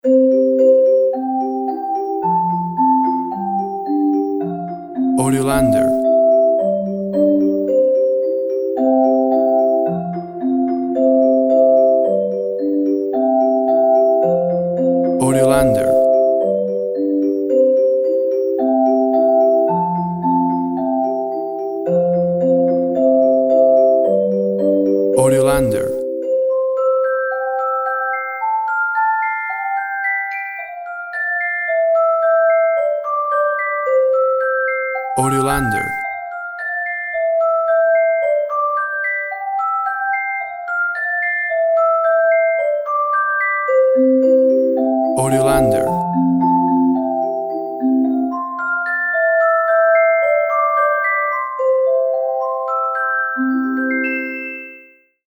A playful and exciting Celeste version
Nursery Rhyme
WAV Sample Rate 16-Bit Stereo, 44.1 kHz
Tempo (BPM) 110